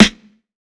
TC SNARE 03.wav